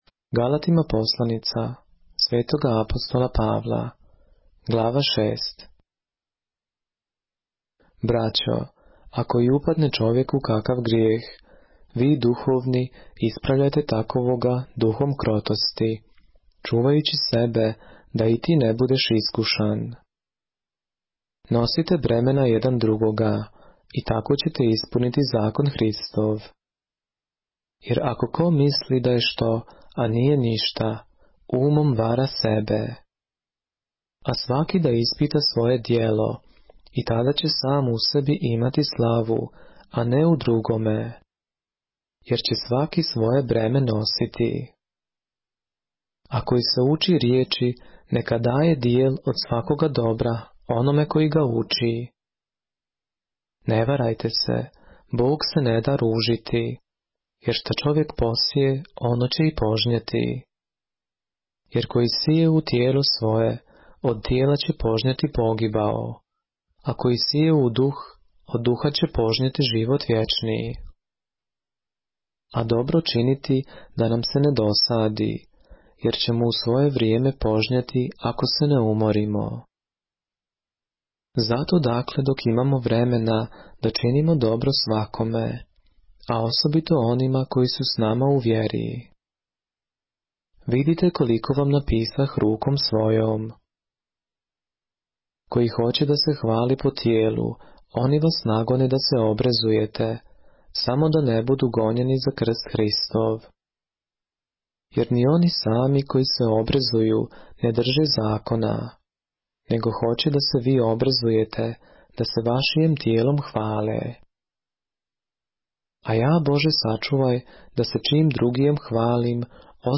поглавље српске Библије - са аудио нарације - Galatians, chapter 6 of the Holy Bible in the Serbian language